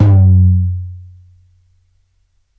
Index of /4 DRUM N BASS:JUNGLE BEATS/KIT SAMPLES/DRUM N BASS KIT 1
FLOOR TOM LONG.wav